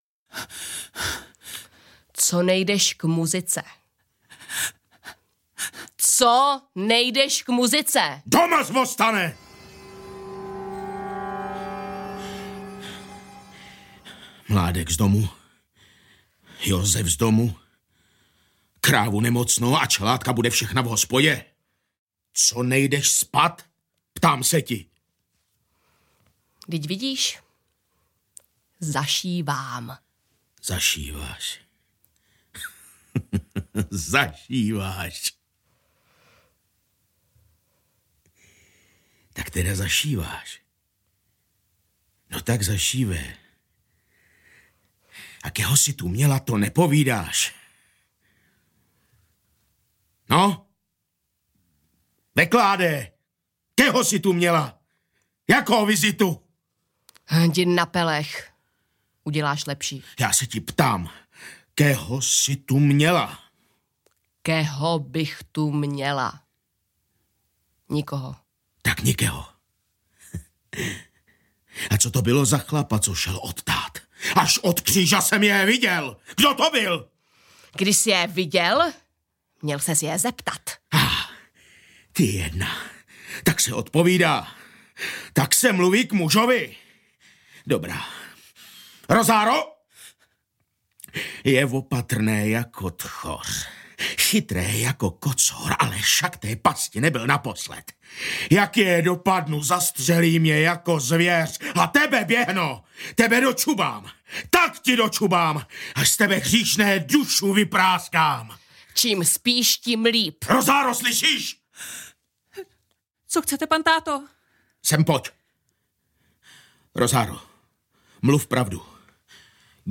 Maryša audiokniha
Ukázka z knihy